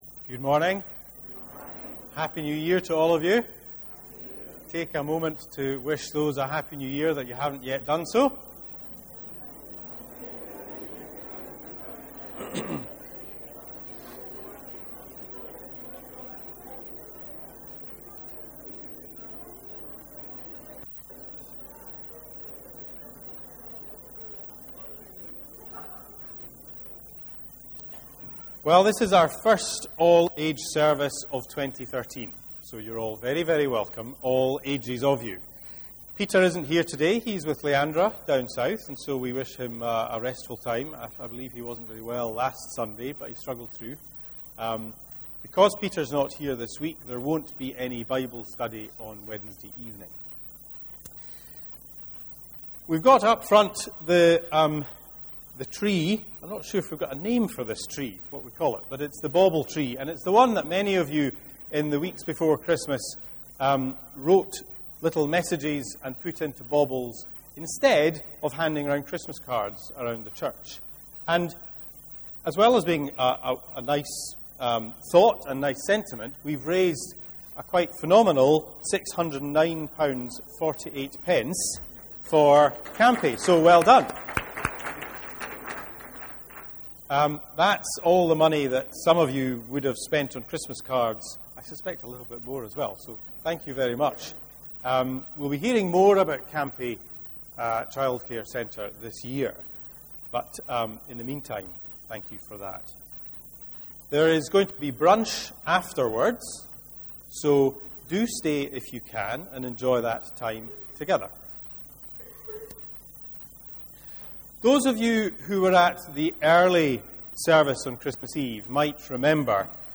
06/01/13 sermon – Family service (Matthew 20:16)
Unfortunately due to a technical issue, we were unable to record the full service.